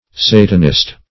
Search Result for " satanist" : Wordnet 3.0 NOUN (1) 1. an adherent of Satan or Satanism ; [syn: Satanist , diabolist ] The Collaborative International Dictionary of English v.0.48: Satanist \Sa"tan*ist\, n. 1.